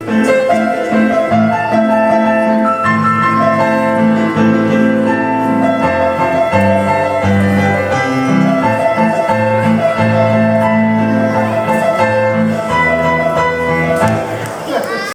piano
Concert à l'école.
prénom composé de deux notes de musique.
impro.mp3